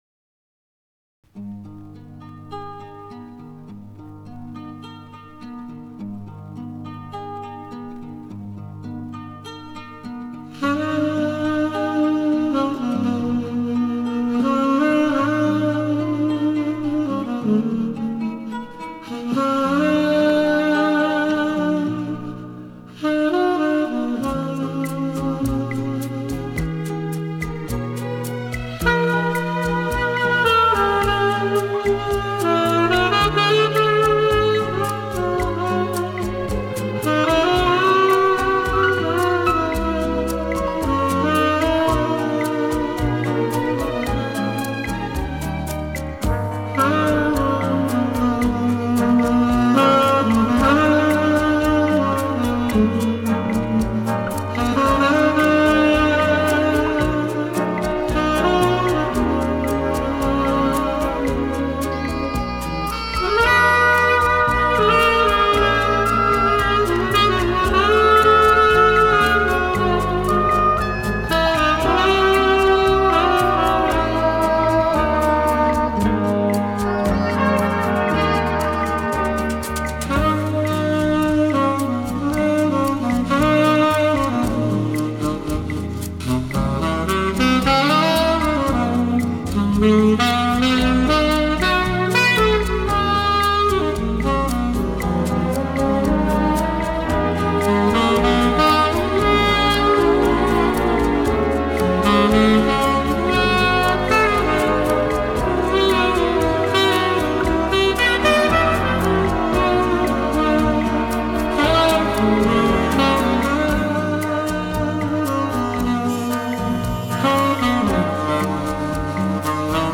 tenor-saks